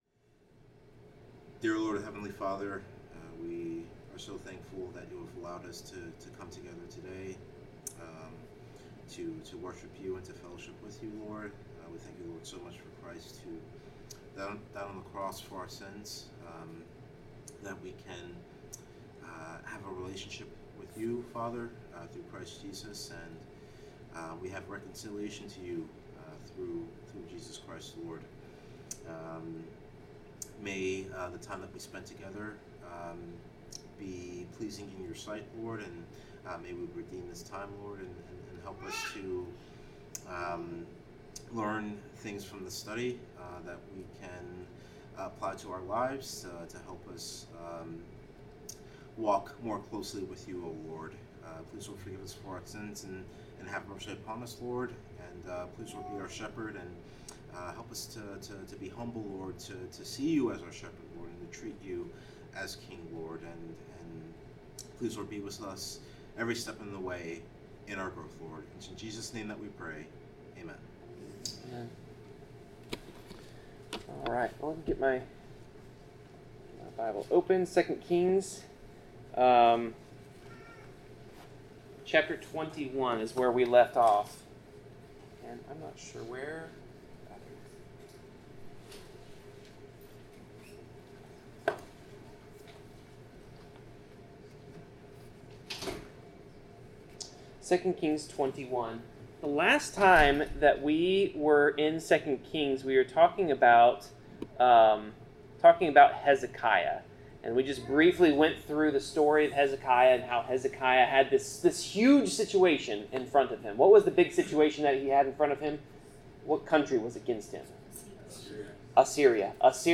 Bible class: 2 Kings 22-23
Passage: 2 Kings 22-23 Service Type: Bible Class